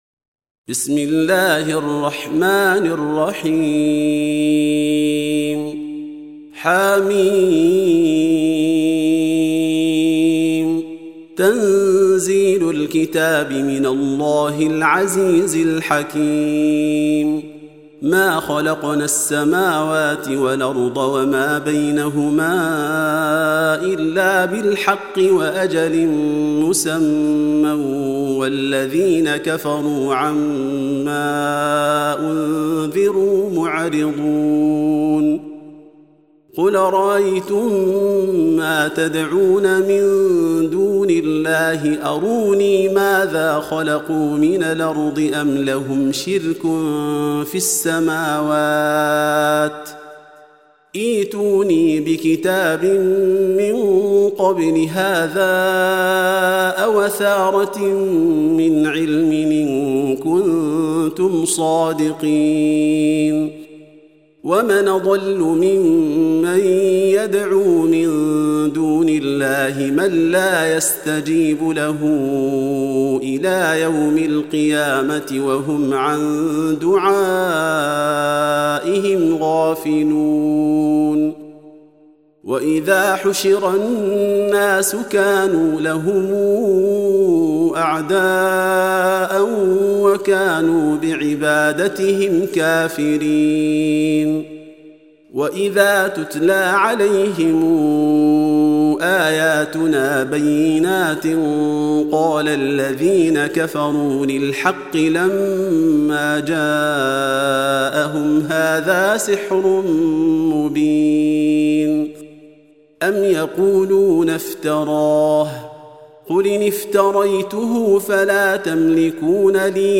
Audio Quran Tarteel Recitation
Surah Repeating تكرار السورة Download Surah حمّل السورة Reciting Murattalah Audio for 46. Surah Al-Ahq�f سورة الأحقاف N.B *Surah Includes Al-Basmalah Reciters Sequents تتابع التلاوات Reciters Repeats تكرار التلاوات